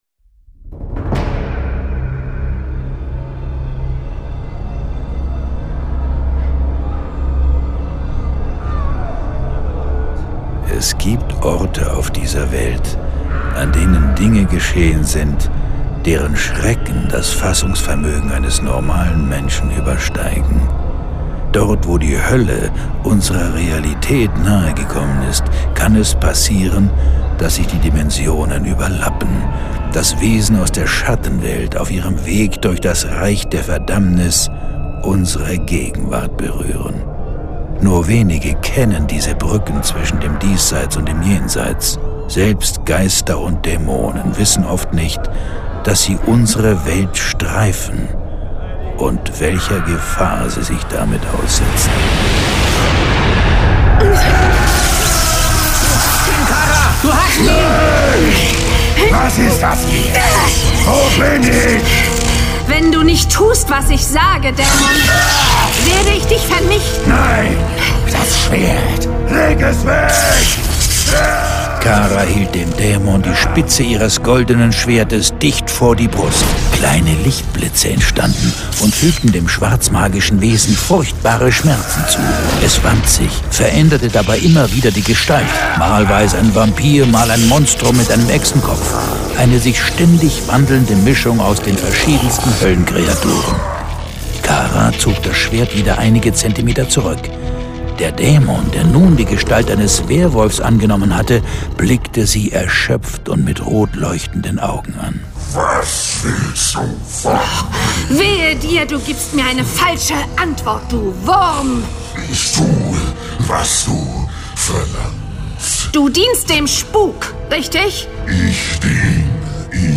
John Sinclair - Folge 57 Im Jenseits verurteilt (I/II). Hörspiel.